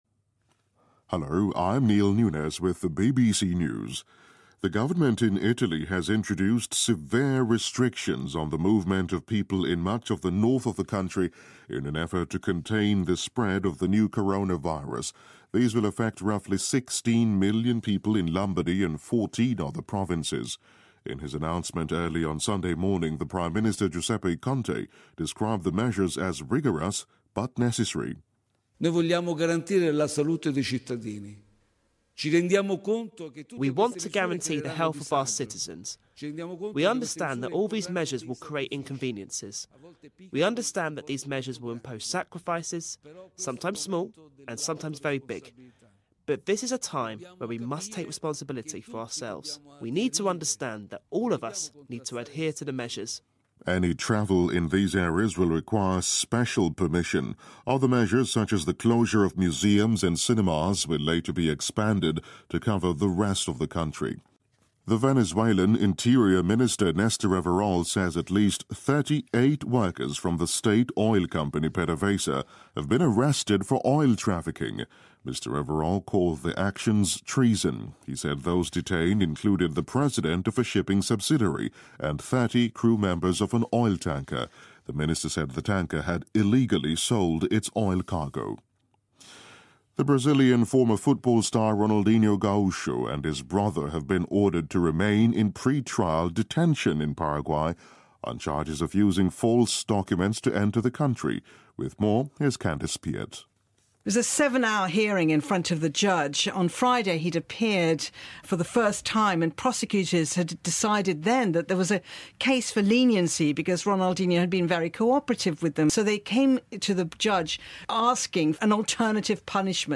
News
英音听力讲解:意大利加强人员流动管制应对新冠疫情